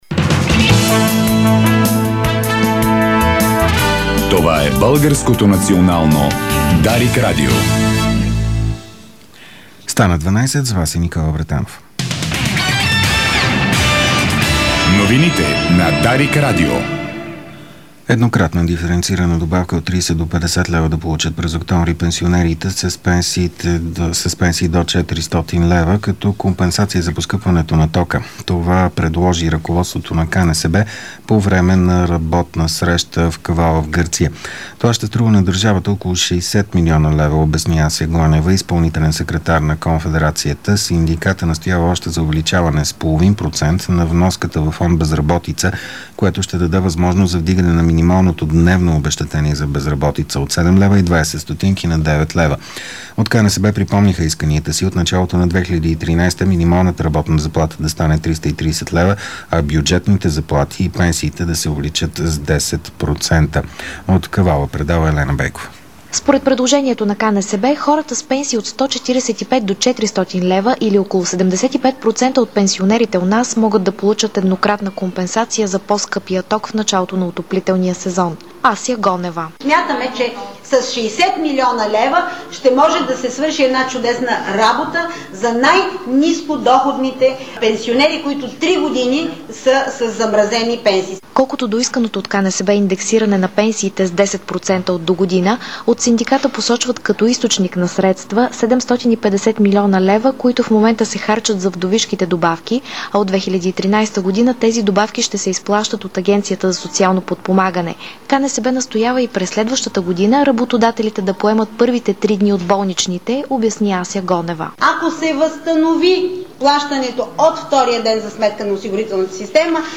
Обедна информационна емисия - 03.07.2012